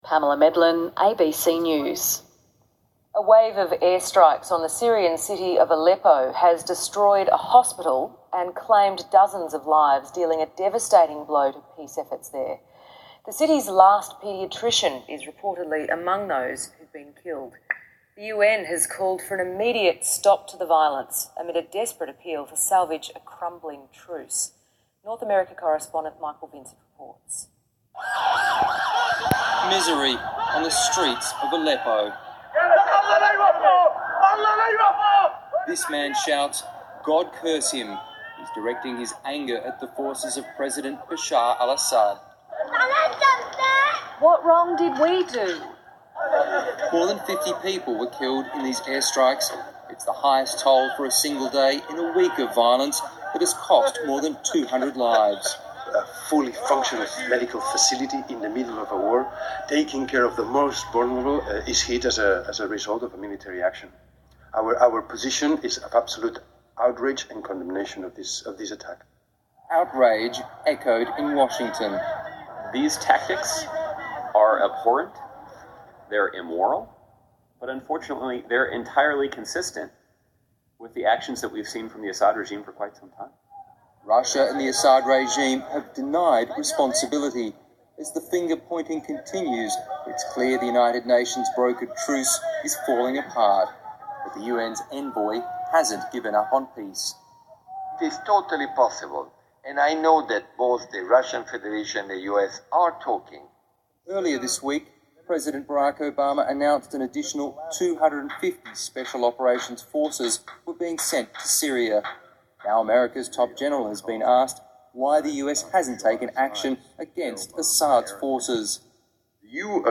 Below: Audio recording of item on ABC TV news (VIC), Friday 29 April, which relates to the alleged bombing of a hospital in rebel-held Aleppo